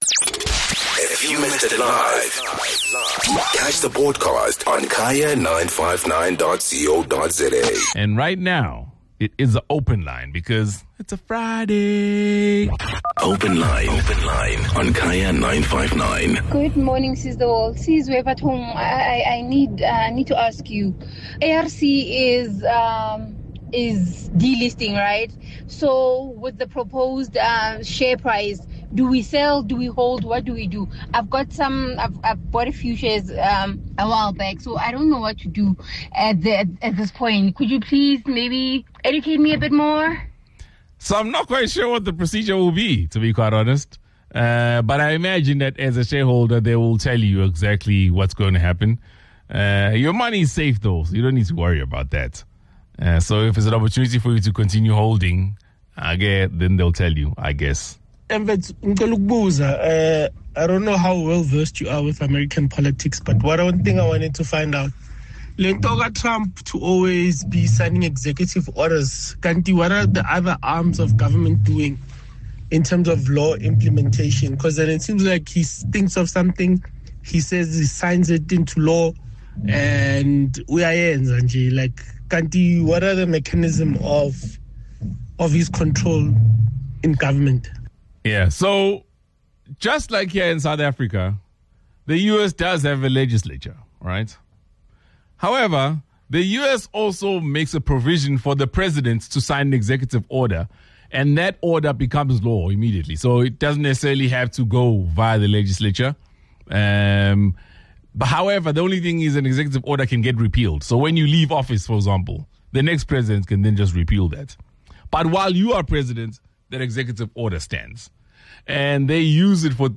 On The Open Line, the team and listeners discussed, amongst others, what happens to shareholders when businesses they had shares in go under or are sold; Trump signing executive orders; questions on why immunization for kids stops at age 12; parent who needed advice on her child who doesn't want to further his studies after passing matric; and feelings on crosses that are placed on side of the road to honoured those who died on the roads.